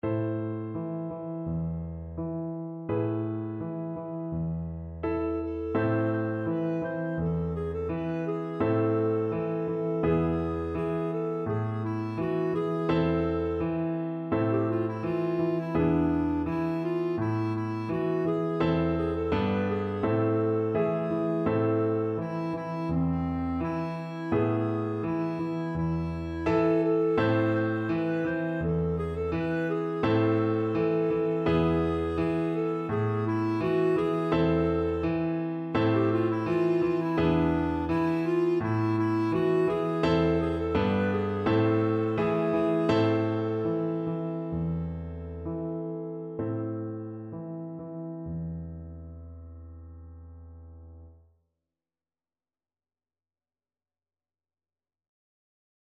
Christmas Christmas Clarinet-Violin Duet Sheet Music Pat-a-Pan (with piano)
ViolinClarinetPiano
A minor (Sounding Pitch) B minor (Clarinet in Bb) (View more A minor Music for Clarinet-Violin Duet )
2/2 (View more 2/2 Music)
Steady two in a bar ( = c. 84)
Clarinet-Violin Duet  (View more Easy Clarinet-Violin Duet Music)
Traditional (View more Traditional Clarinet-Violin Duet Music)